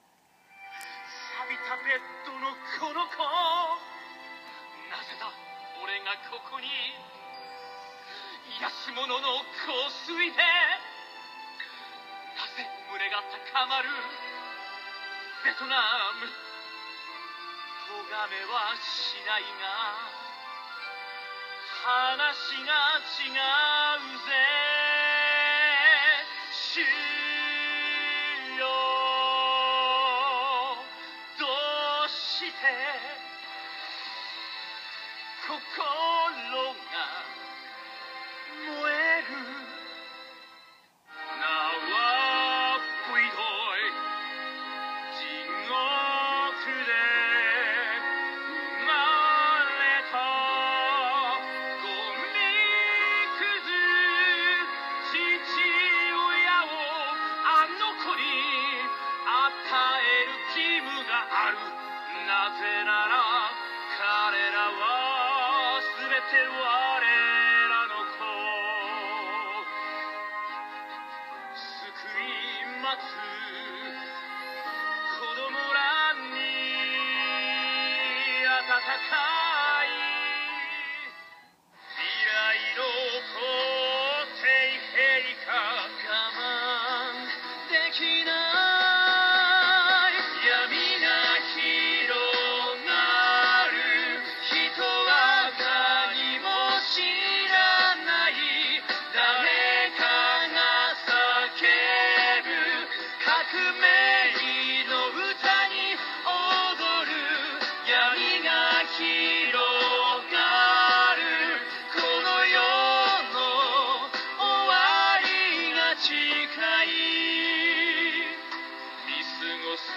ミュージカルCD
Chorus
Keyboard
Guitars
Bass
Drums
Violin
English Horn